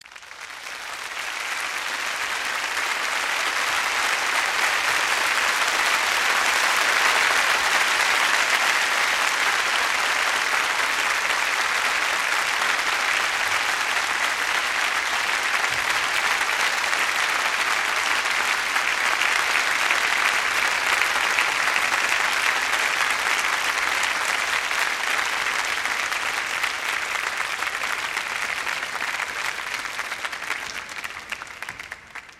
Другие рингтоны по запросу: | Теги: аплодисменты, Applause
Категория: Различные звуковые реалтоны